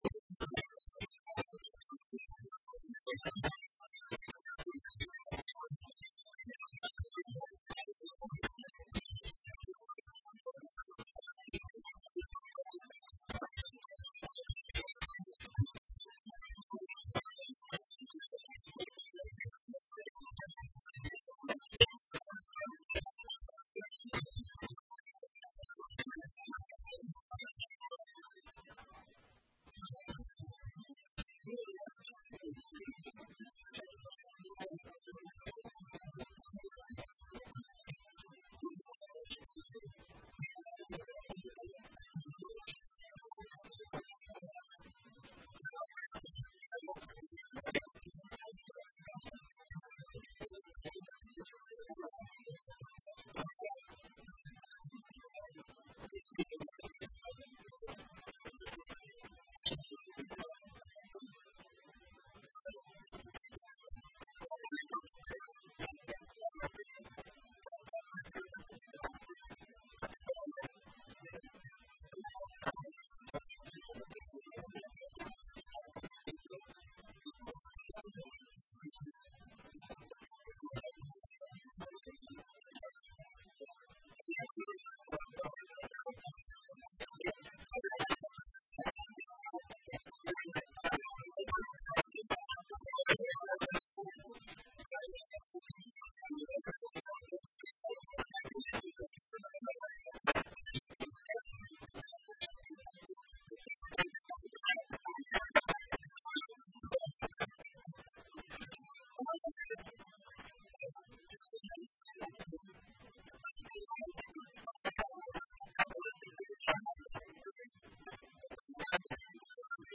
Periodistas